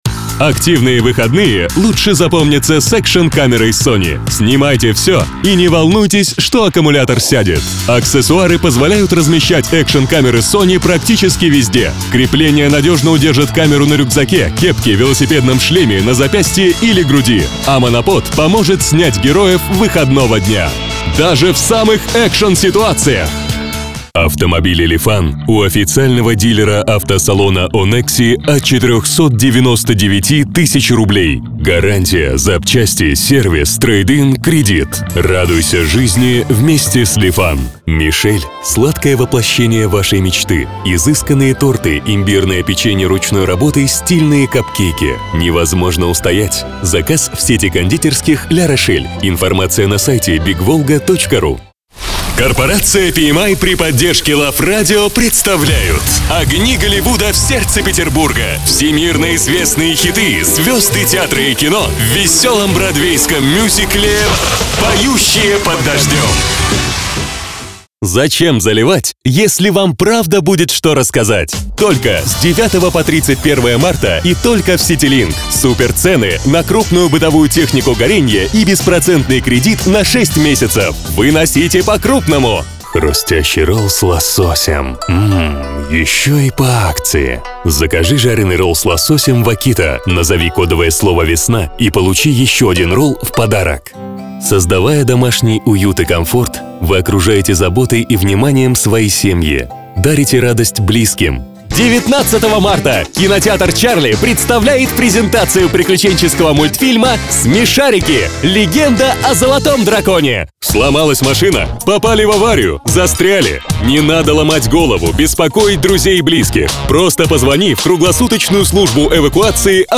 Мужской
Тембры: баритон, тенор. Довольно широкий диапазон, вокал.
Микрофоны: Recording Tools MC-900, SE Electronics 2200a,
Sennheiser MD 441 U
Обработка: Drawmer MX60
Звуковая карта: TC Electronics Impact Twin
Акустическая кабина.